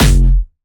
Jumpstyle Kick 3